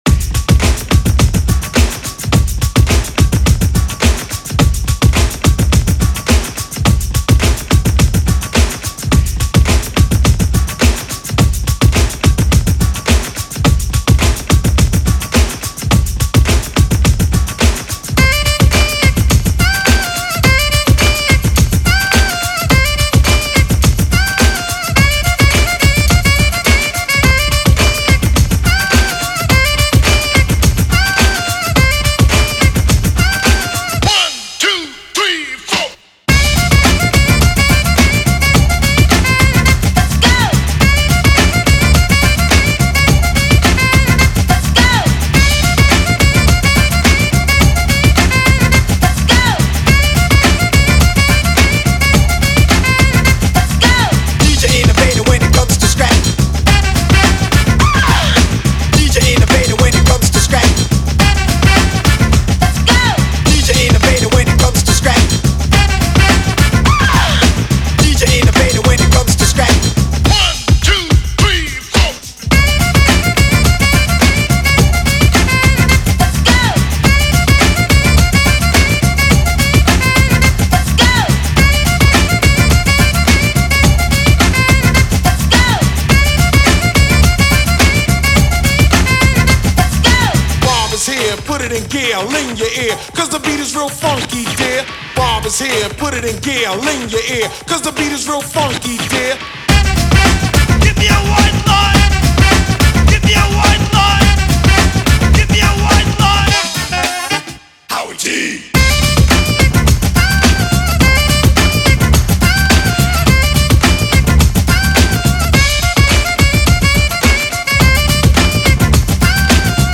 Feel the Classic Eurodance Energy in Miami